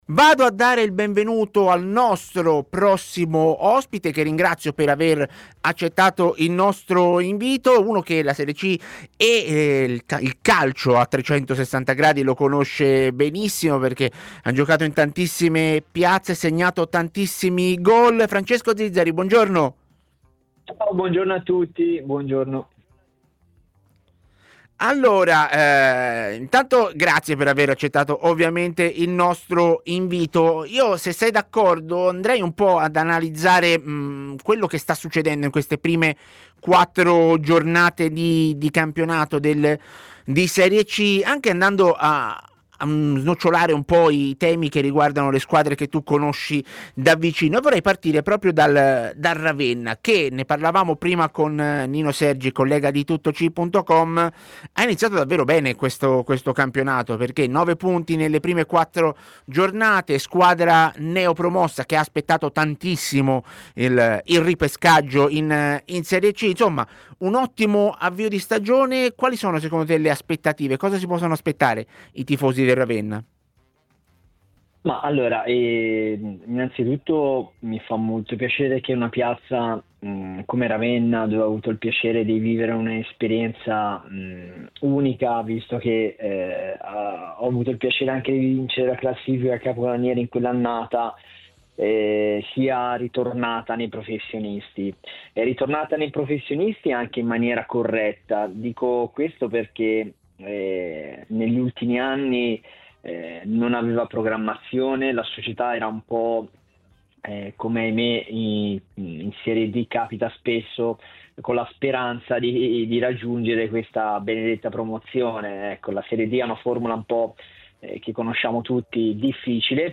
trasmissione in onda su TMW Radio e su Il 61, canale 61 del digitale terrestre. Partirei da un’analisi di queste prime quattro giornate di campionato di Serie C, concentrandoci anche su alcune squadre che conosci bene.